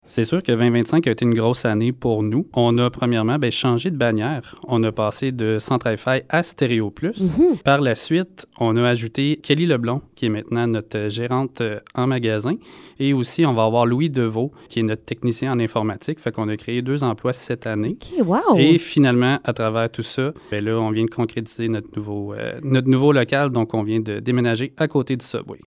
en entrevue dans l’émission du matin à CIGN fm